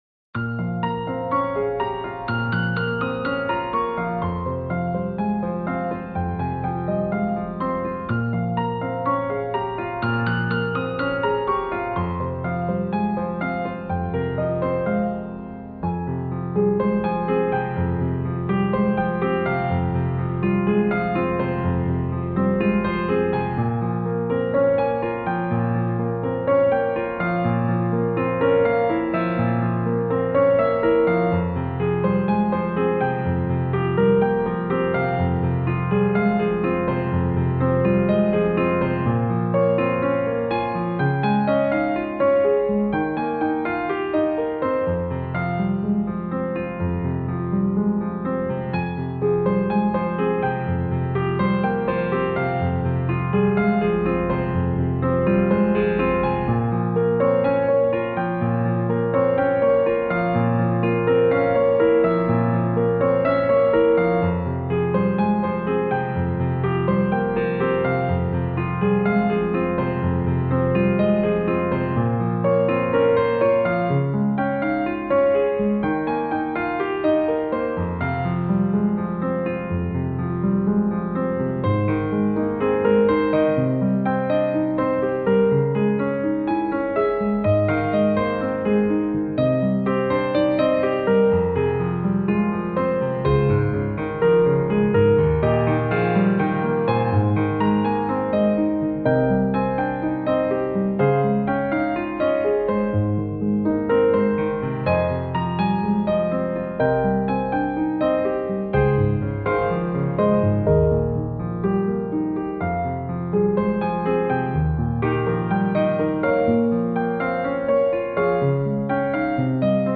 新世纪钢琴
音乐风格：New Age